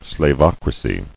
[slav·oc·ra·cy]